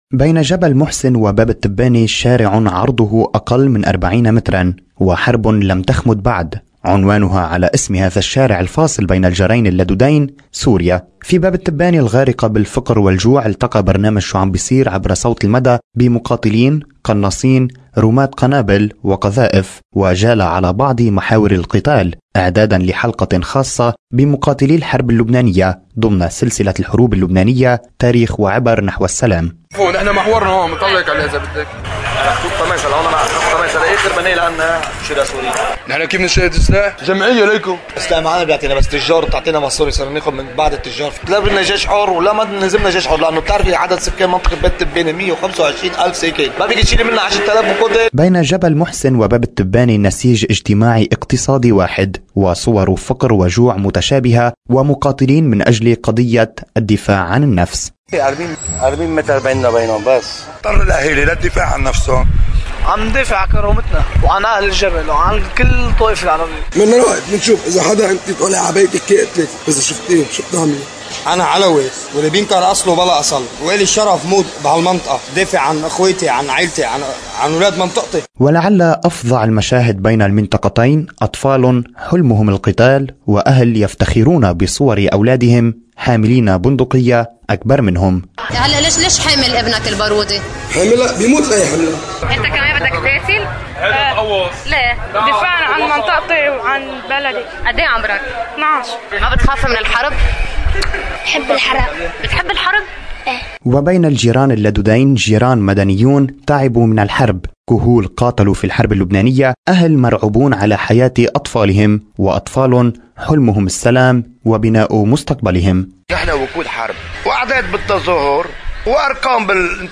جولة مع مقاتلي باب التبانة وجبل محسن ولقاءات مع اطفال حلمهم القتال ومدنيين حلمهم الاستقرار